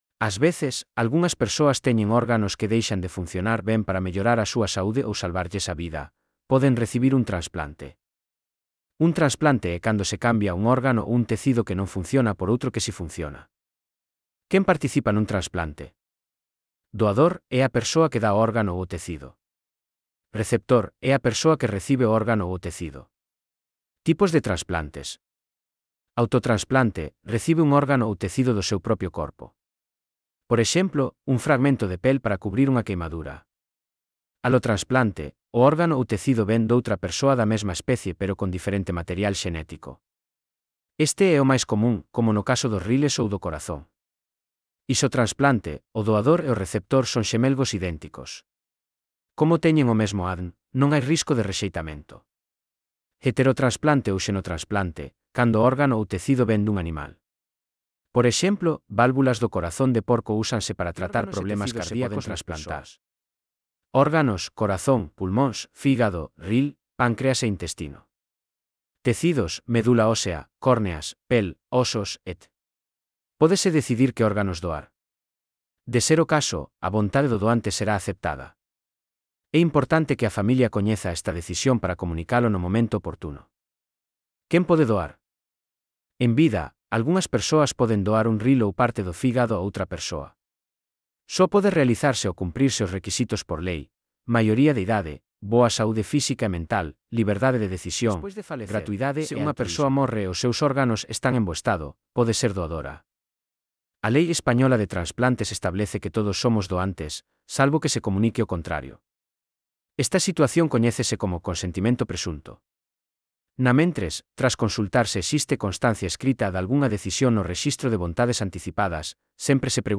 Lectura facilitada
Elaboración propia coa ferramenta Narakeet.